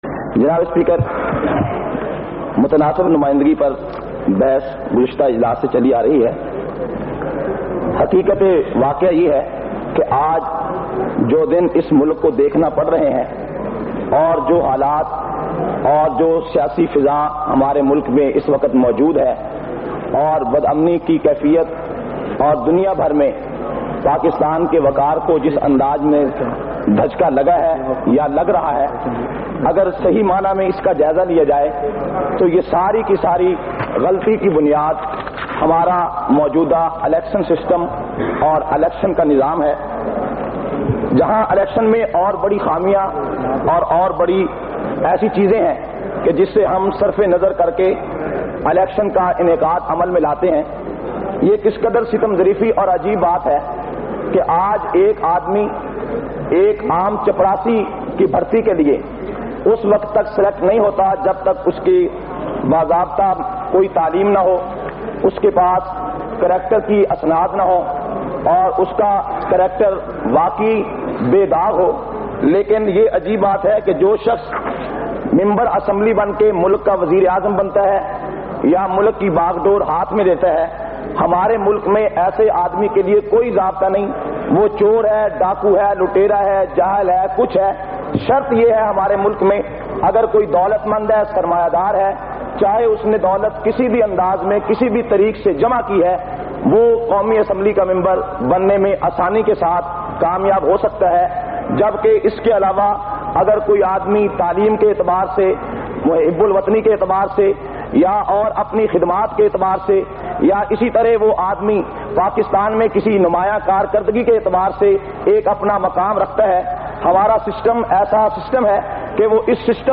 447- Qaomi Assembly Khutbat Vol 1.mp3